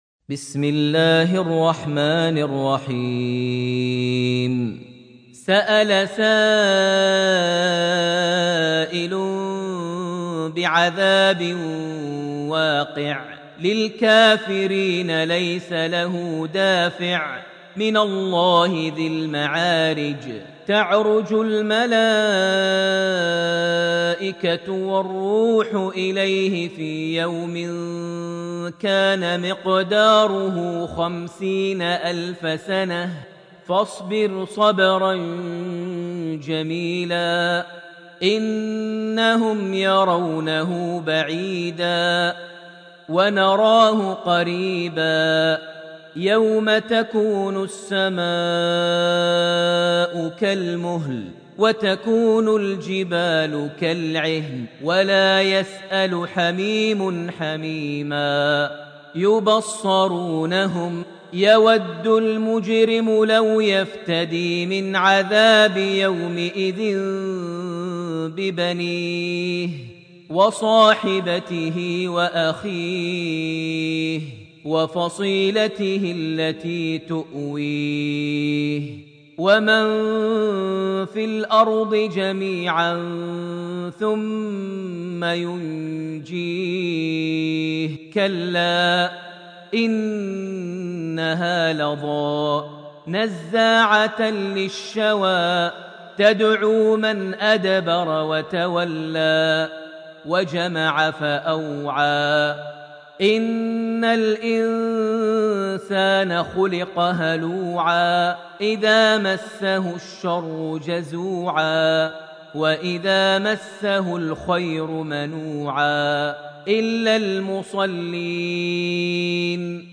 Surat Al-Maarij > Almushaf > Mushaf - Maher Almuaiqly Recitations